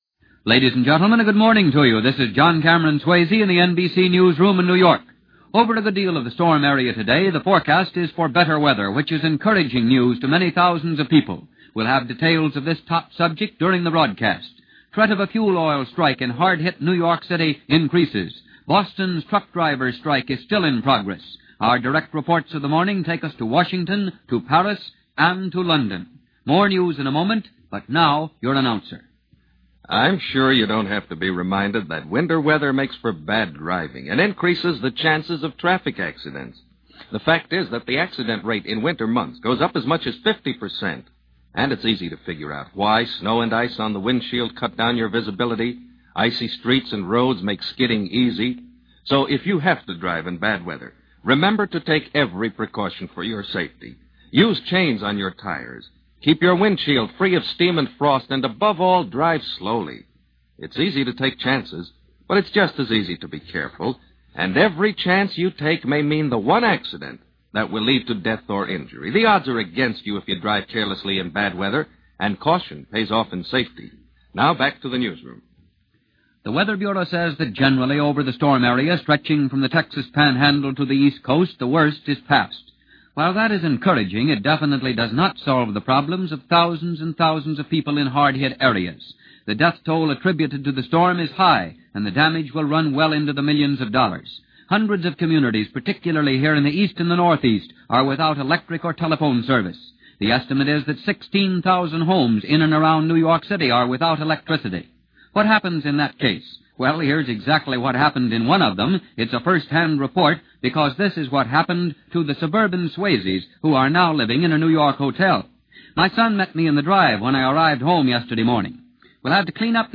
OTR Christmas Shows - News - 1948-01-01 NBC